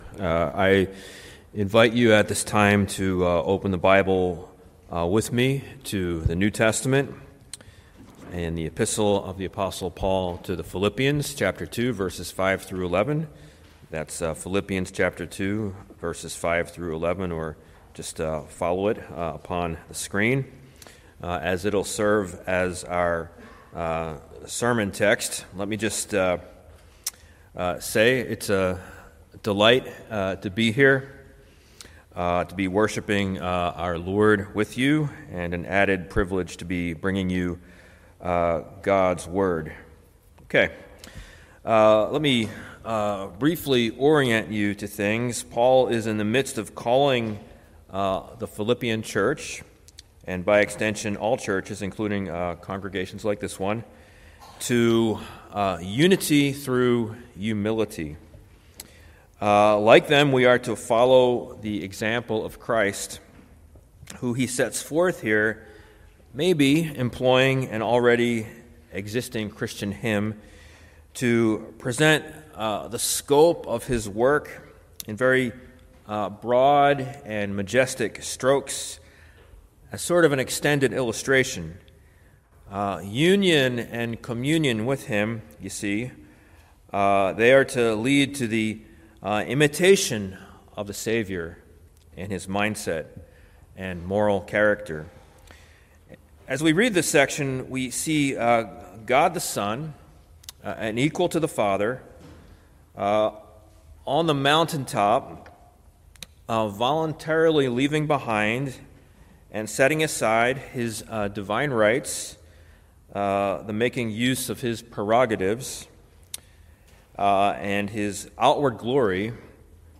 Sermons preached at Redeemer Church in Chambersburg